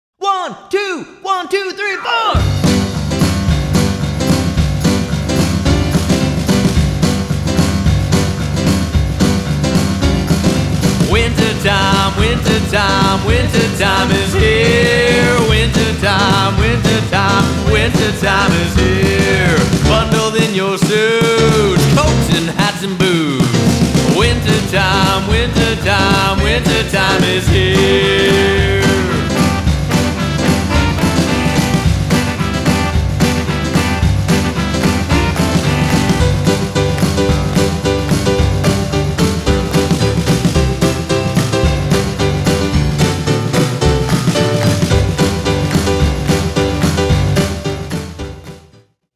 -- all-ages holiday music